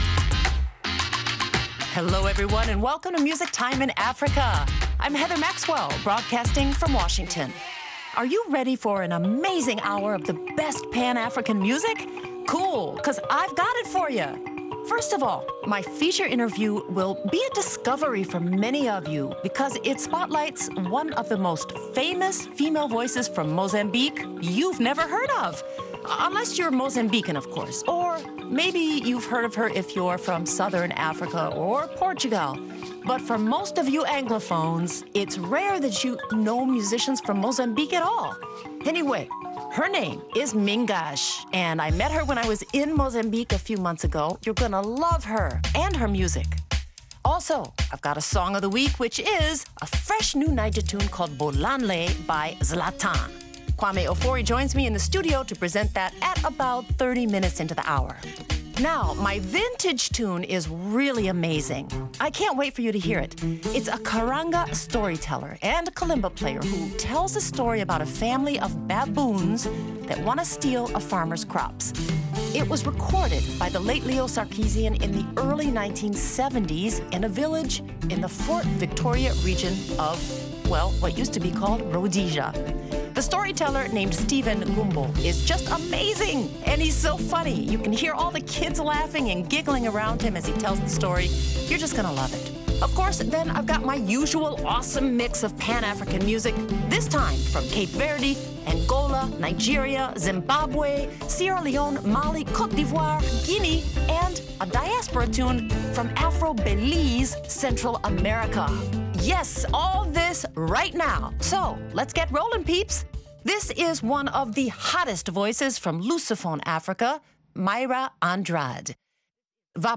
karanga storyteller and kalimba player
His baboon story which he tells to music in front of a live crowd will fascinate and entertain you for sure.